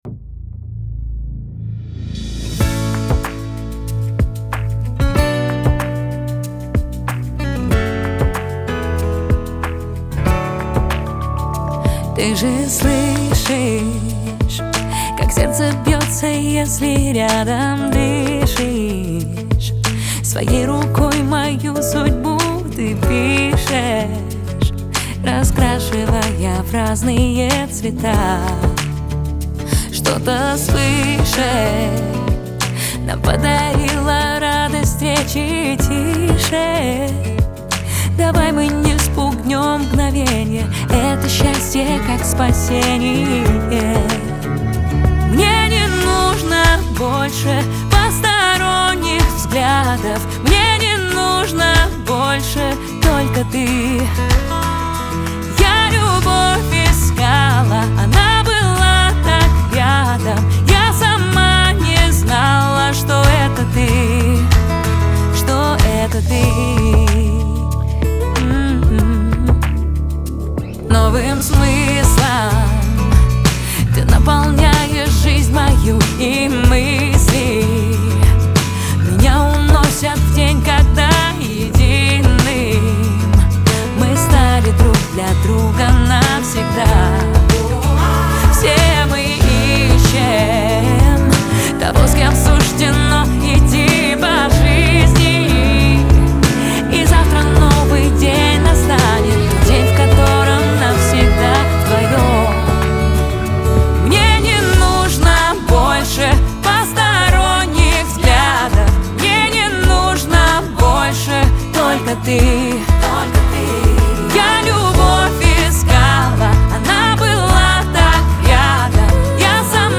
проникновенная поп-баллада